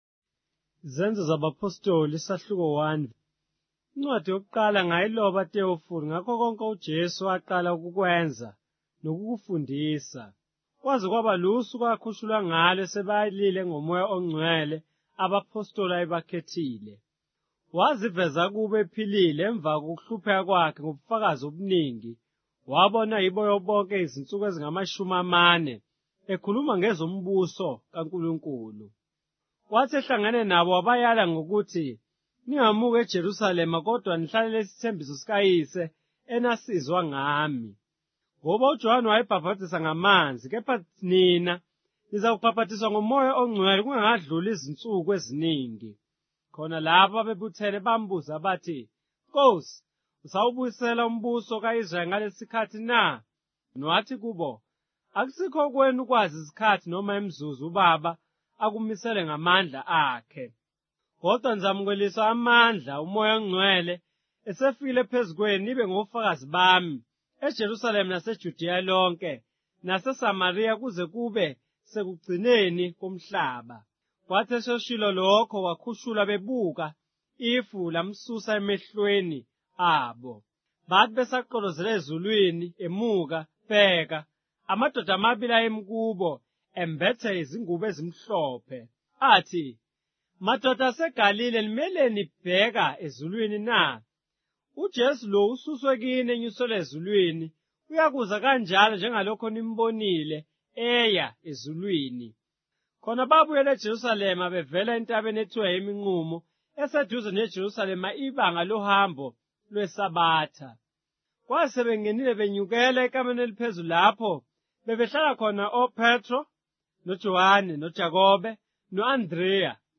Isahluko yeBhayibheli Zulu, ne ukulandisa okulalelwayo